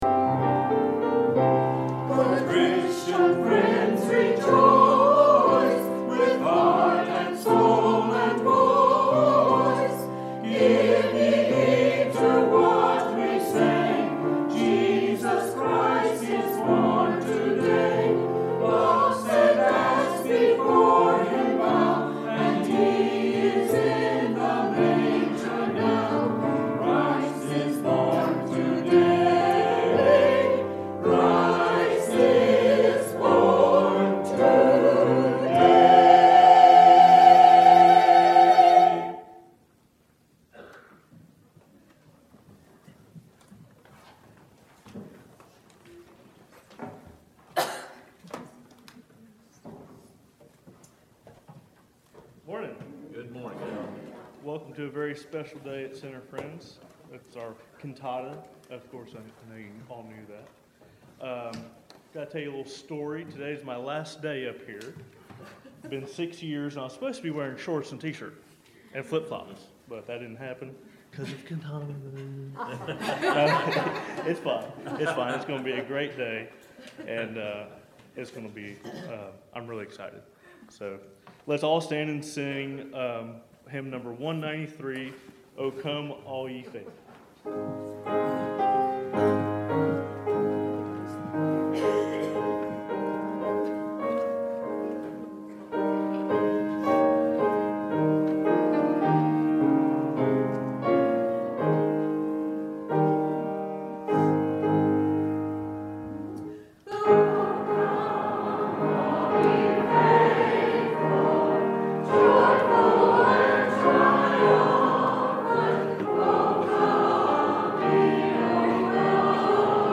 Bible Text: Luke 2: 1 – 20 | Minister: Choir